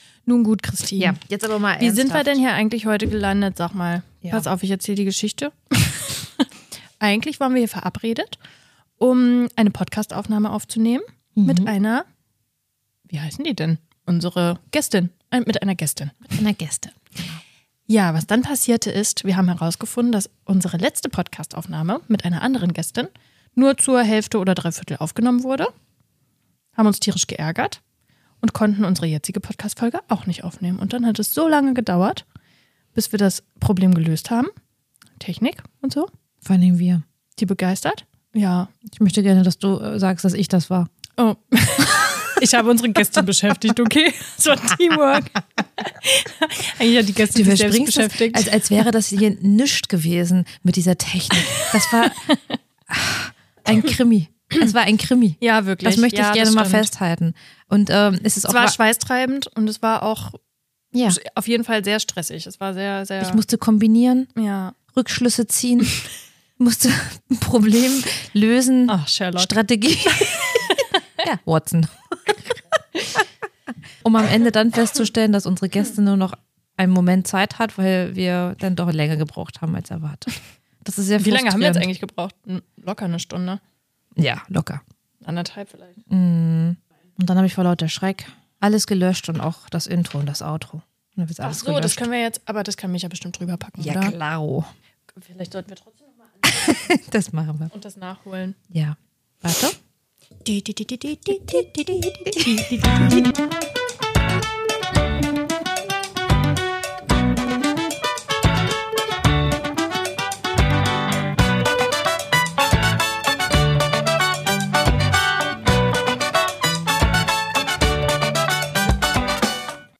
Was wie ein Krimi beginnt (Internet gelöscht! Technik-Fail! Dramatische Musik im Kopf!), endet in einer richtig persönlichen Folge – ganz ohne Gäste, dafür nur mit uns Zwei Wir sprechen über unsere Reflexprofile und wie sie unsere Kindheit geprägt haben, nehmen euch mit auf unseren Weg durch Weiterbildungen und geben euch ganz nebenbei einen privaten Einblick, den es so noch nicht gab.